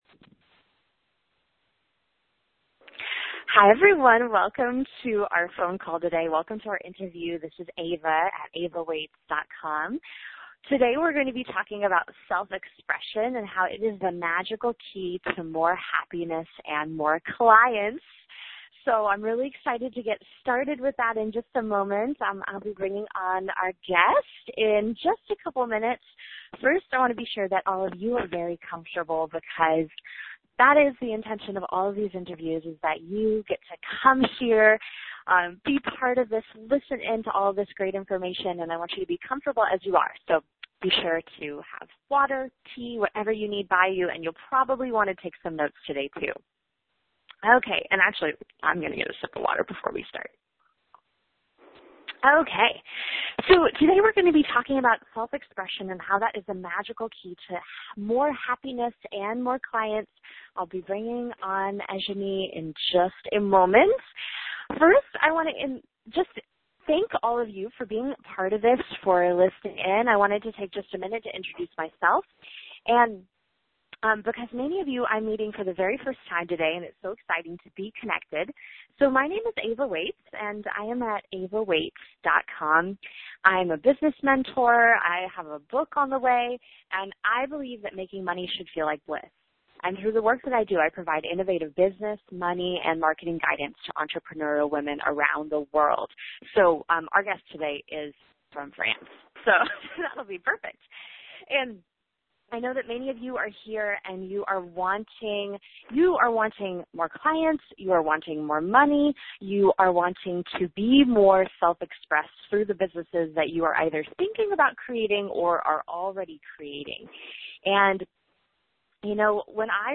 This interview first aired on Wednesday, February 25, 2015